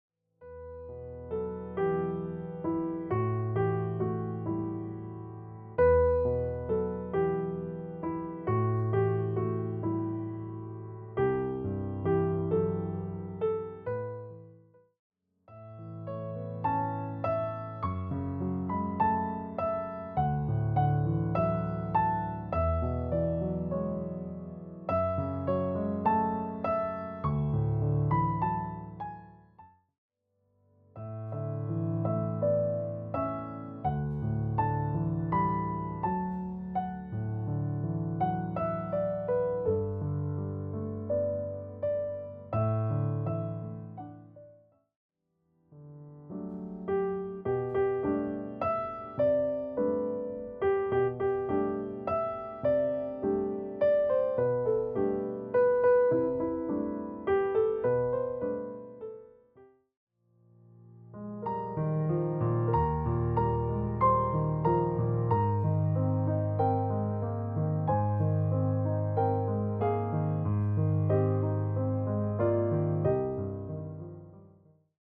The album emphasizes melody and spacious, resonant tones